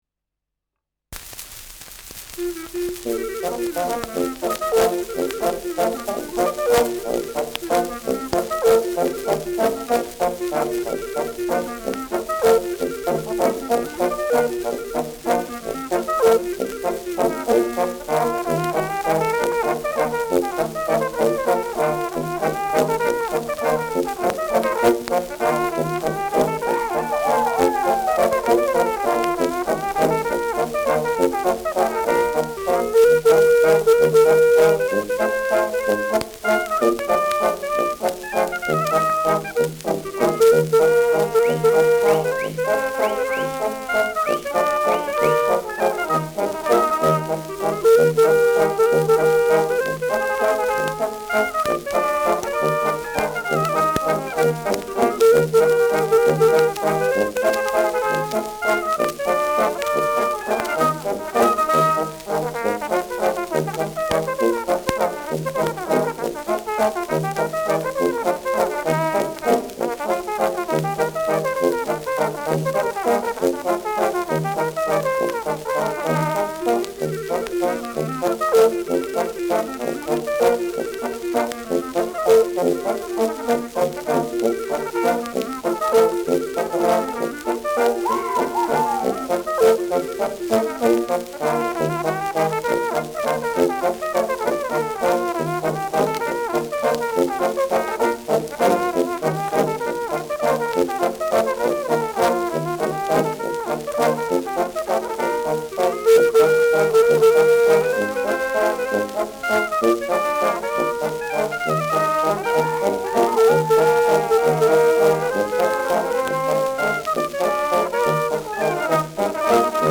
Schellackplatte
Stärkeres Grundrauschen : Gelegentlich leichtes bis stärkeres Knacken : Verzerrt an lauten Stellen